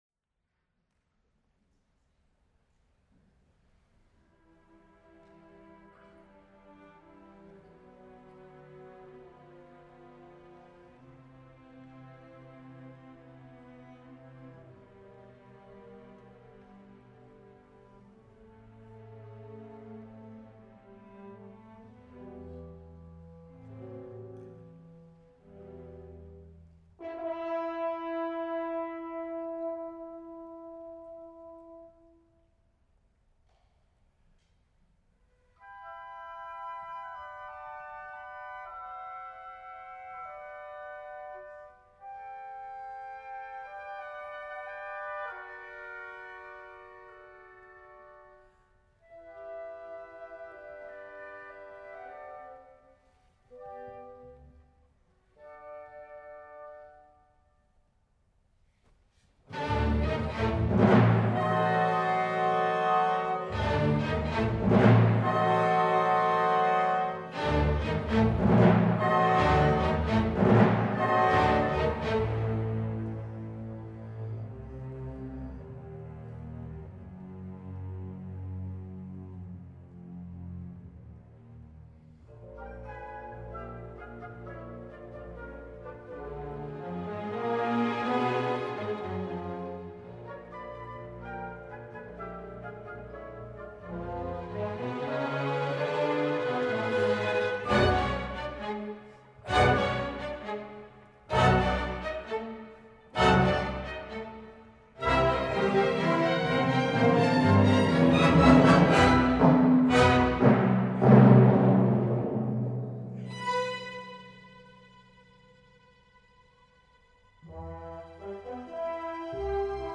Temporada de abono